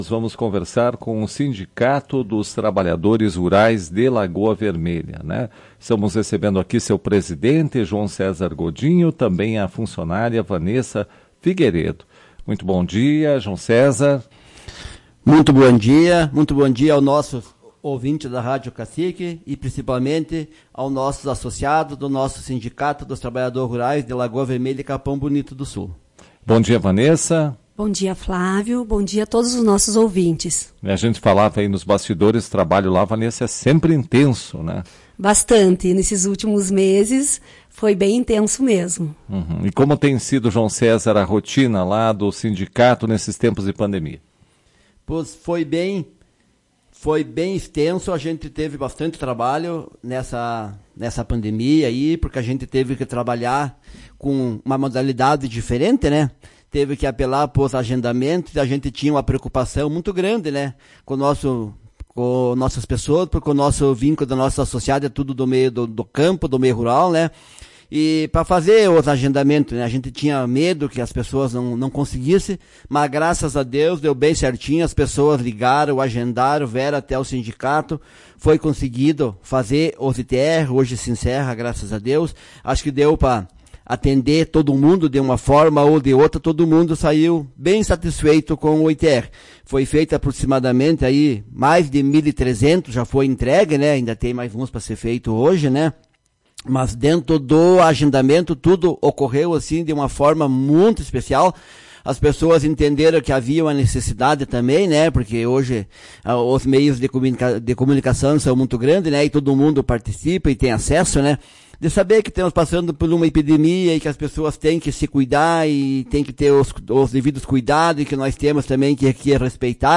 Ouça a entrevista e saiba mais sobre os assuntos.